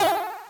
jumpL1.ogg